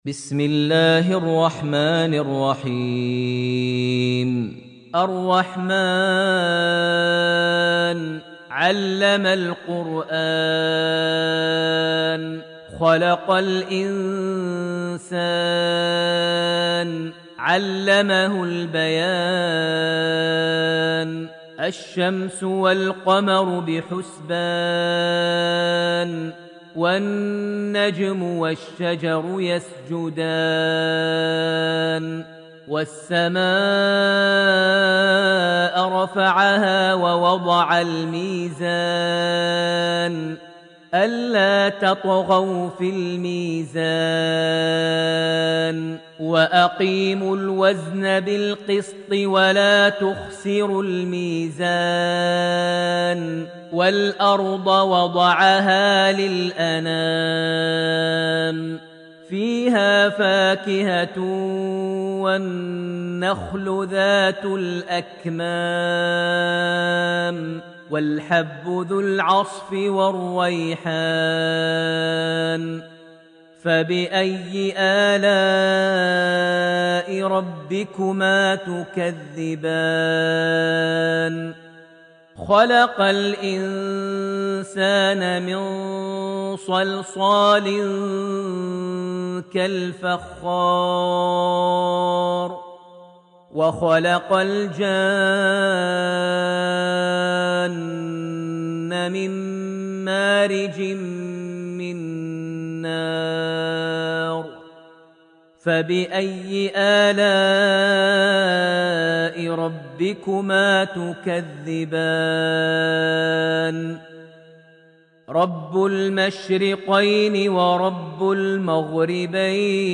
Surah AlRahman > Almushaf > Mushaf - Maher Almuaiqly Recitations